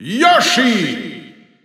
Announcer saying Yoshi's name in German from Super Smash Bros. 4 and Super Smash Bros. Ultimate
Yoshi_German_Announcer_SSB4-SSBU.wav